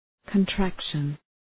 Shkrimi fonetik {kən’trækʃən}